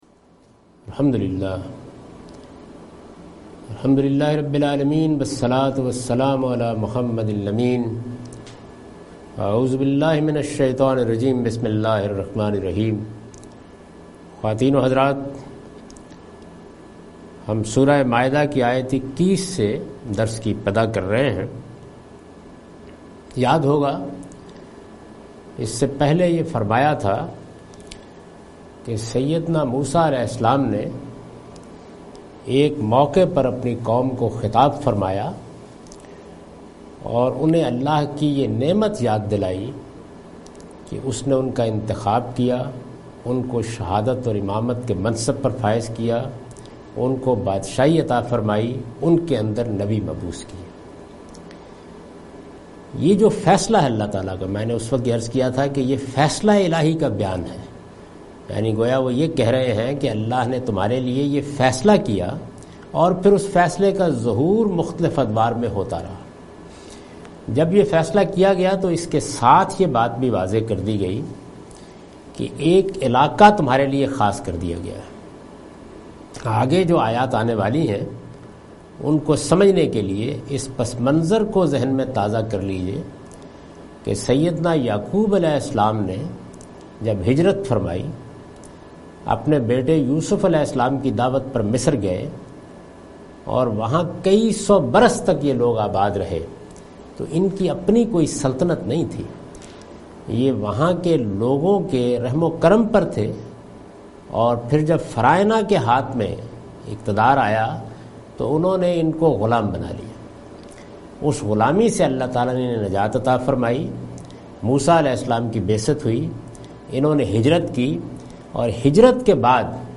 Surah Al-Maidah - A lecture of Tafseer-ul-Quran, Al-Bayan by Javed Ahmad Ghamidi.